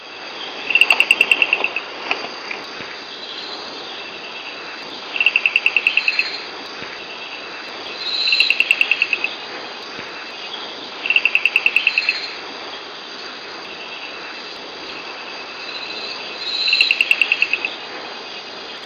To convince a female to have kids with him, a male frog first finds a nice spot – a bamboo stem with a tiny hole just big enough to get through – and then sings to impress.
sound-file-1-call-of-the-white-spotted-bush-frog.mp3